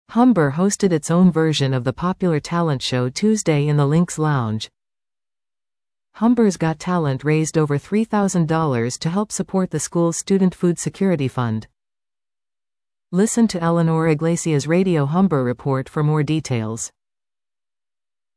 We bring you the sights and sounds from the first ever Humber's Got Talent.